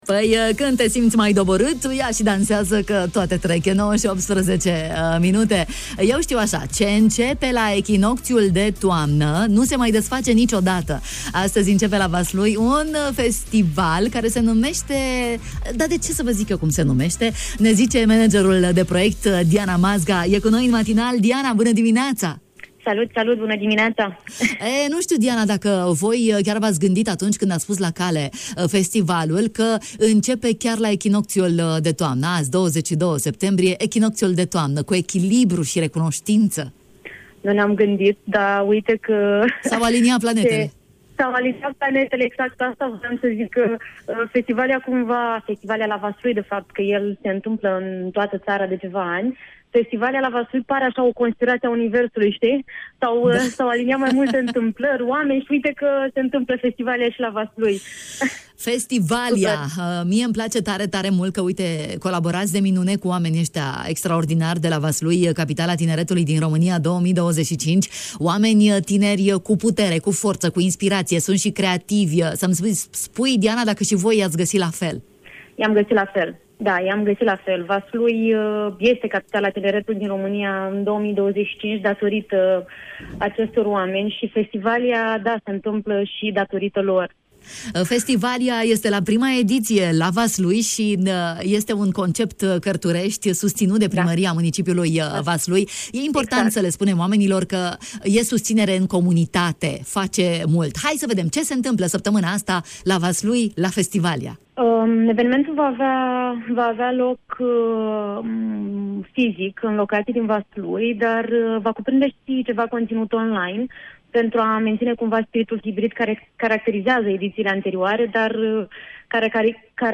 în direct în matinalul de la Radio Iași